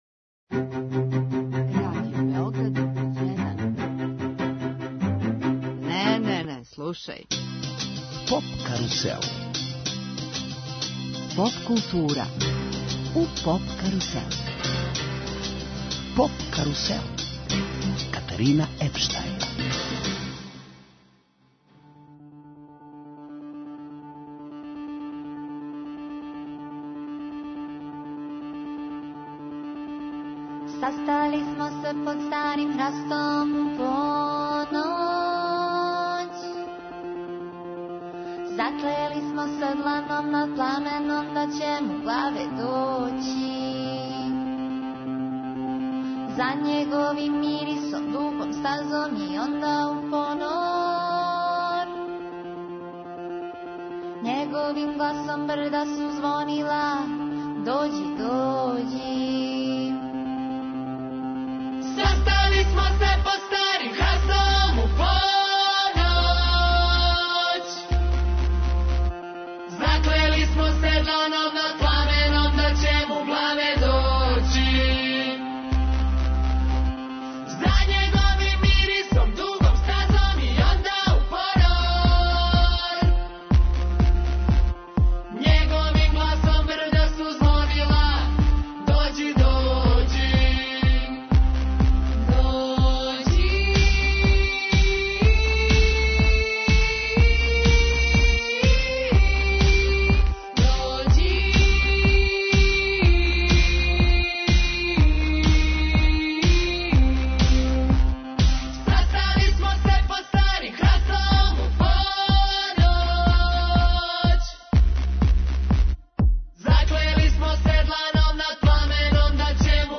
Емитујемо директан програм са највеће европске музичке конференције Еуросоник, која се одржава у Гронингену (Холандија).